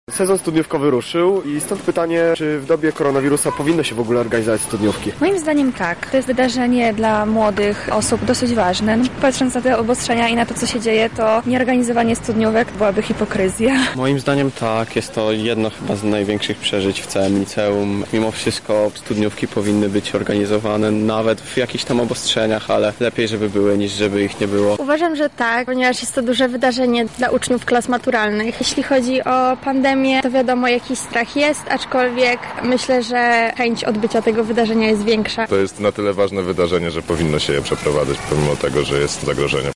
Zapytaliśmy mieszkańców Lublina czy jest to powód do rezygnacji z przedmaturalnych bali.
sonda